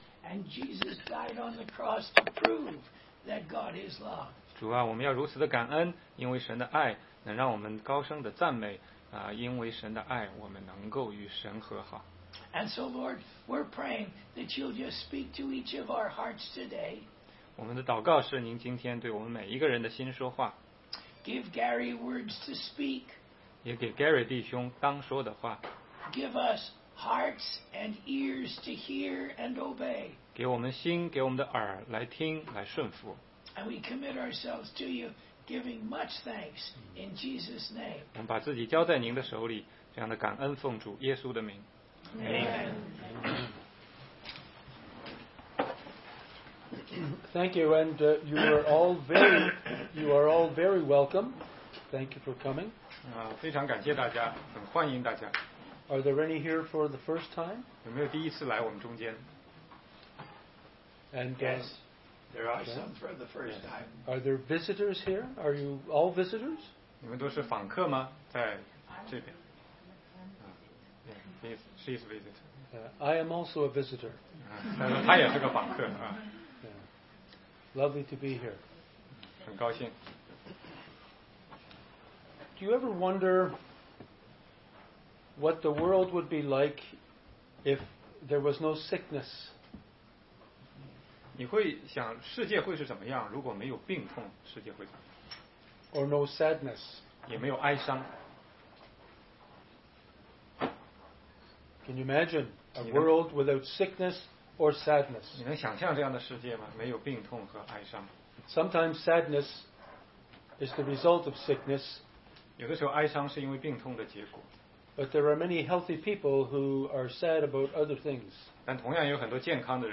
16街讲道录音 - 毕世大池子的瘫子得医治